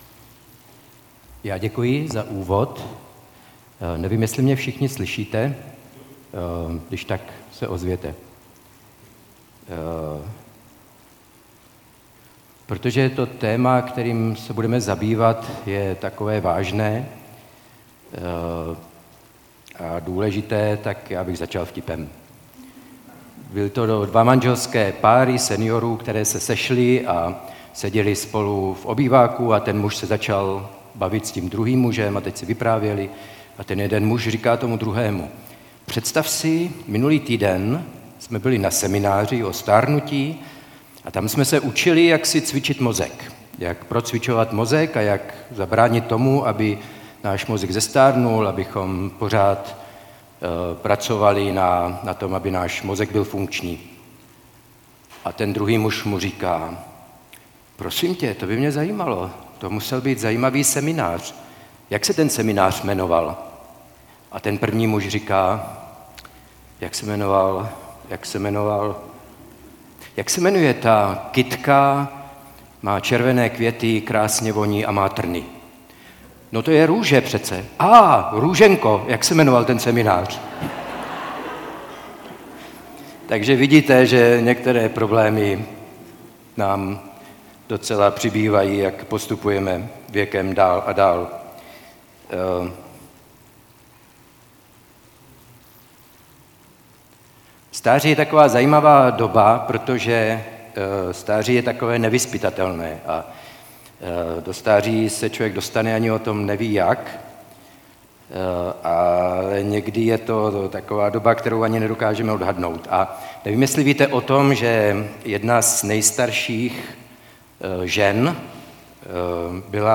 Umění zestárnout - přednáška